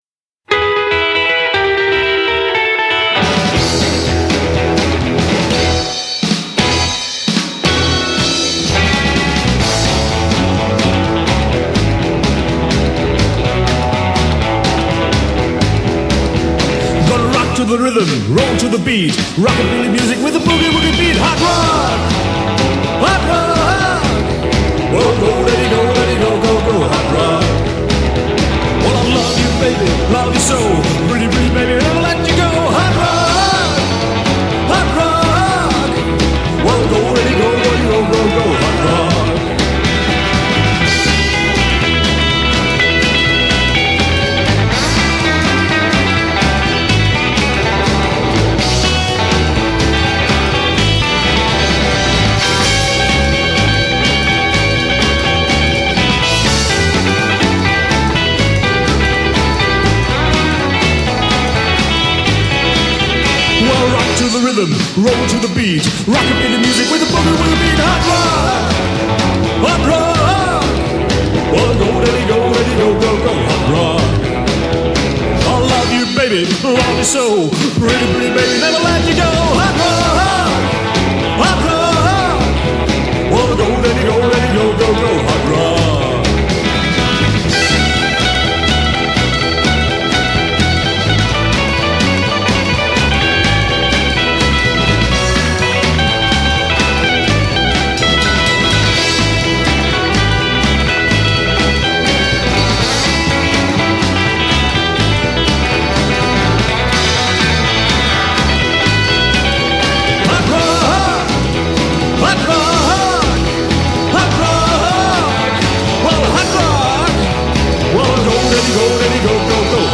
TEDDY BOY ROCKABILLY FROM GERMANY